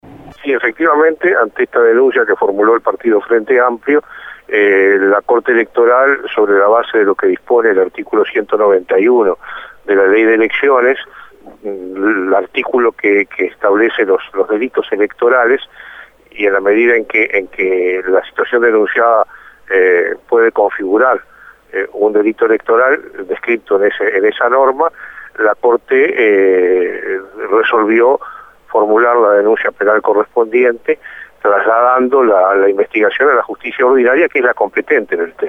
El ministro de la Corte Electoral, Wilfredo Penco, dijo a El Espectador que la Corte Electoral hará una denuncia penal por la aparición de papeletas del "no a la baja", según se resolvió esta tarde.